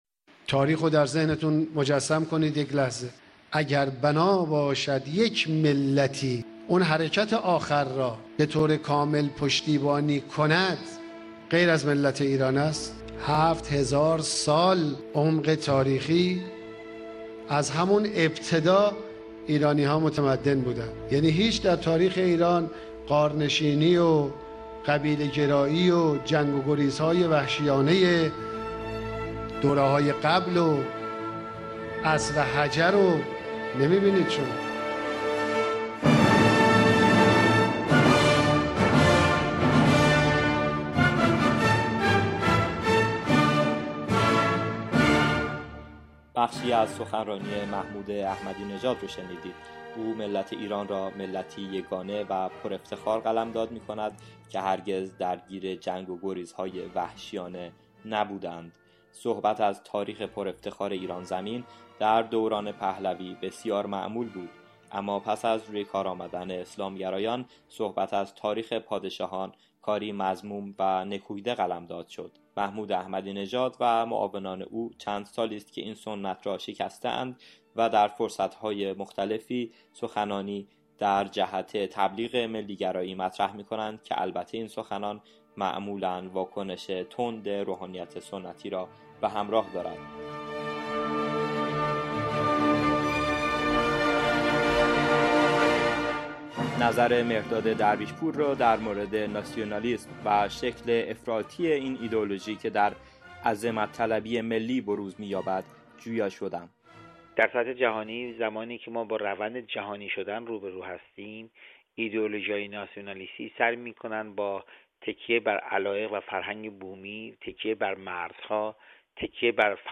Interview-Nationalism.mp3